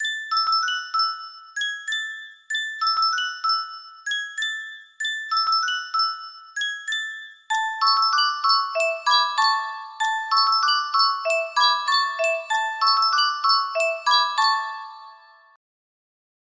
Yunsheng Custom Tune Music Box - Shave and a Haircut music box melody
Full range 60